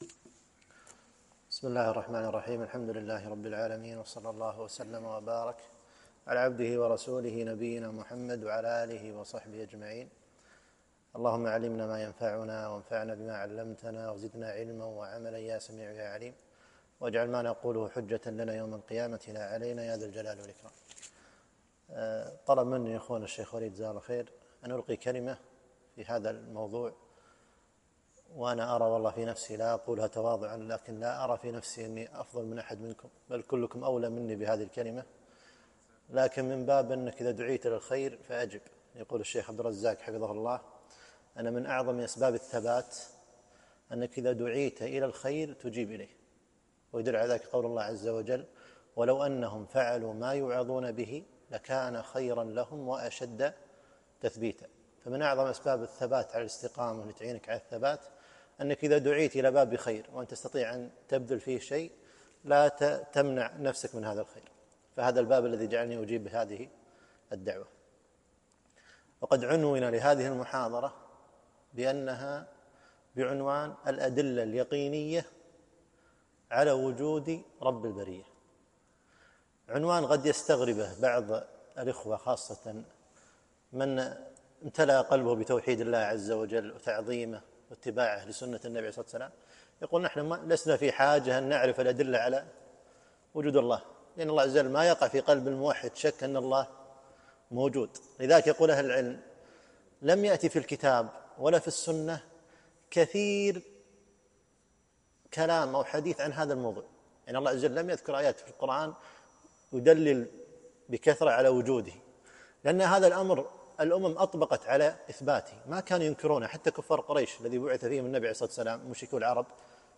محاضرة - الأدلة اليقينية على وجود رب البرية